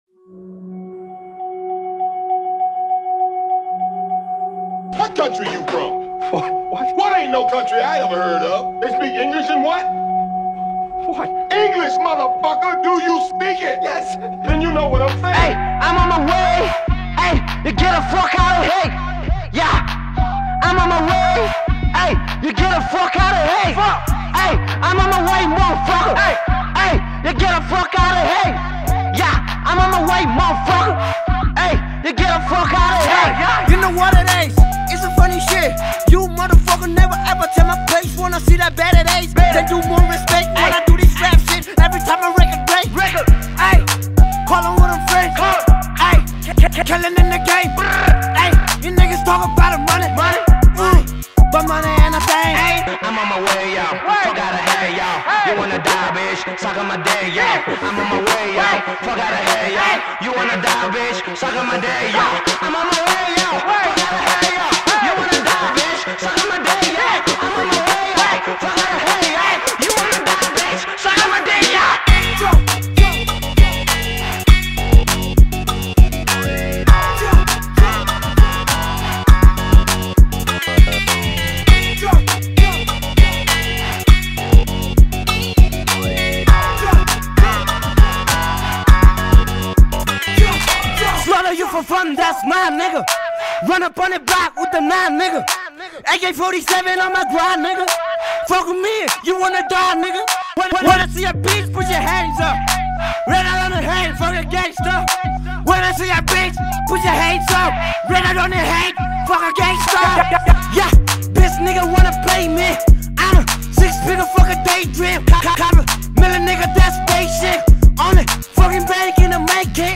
# Nepali Mp3 Rap Song